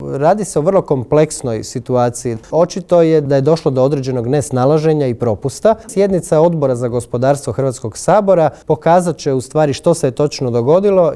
Otkrio nam je u Intervjuu tjedna Media servisa uoči održavanja sjednice Odbora za gospodarstvo upravo na temu prodaje plina višestruko ispod tržišne cijene.